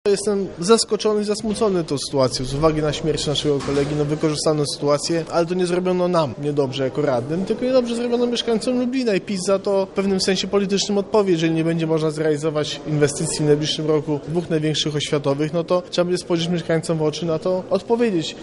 Niezatwierdzony budżet oznacza dla miasta wiele konsekwencji, między innymi zerwanie umów z bankami – komentuje Piotr Kowalczyk, przewodniczący Rady Miasta Lublin
sesja-rady-miasta.mp3